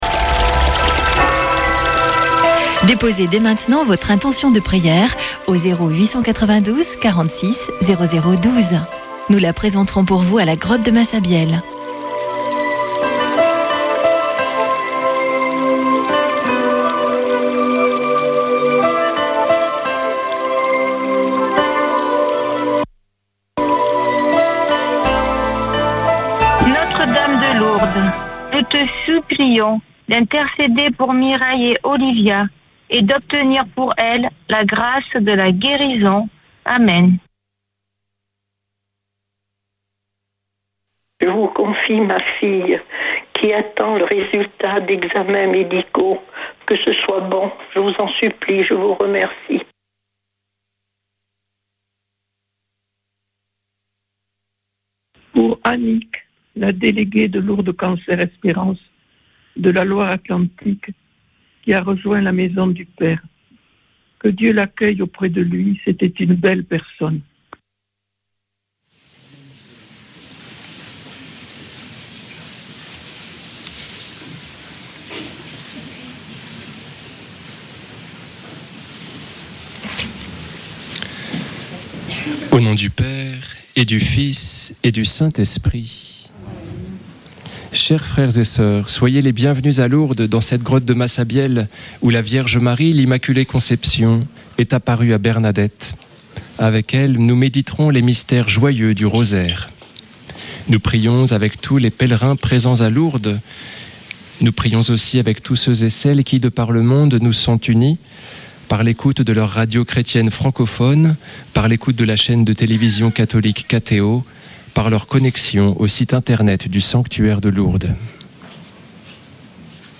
Accueil \ Emissions \ Foi \ Prière et Célébration \ Chapelet de Lourdes \ Chapelet de Lourdes du 09 févr.
Une émission présentée par Chapelains de Lourdes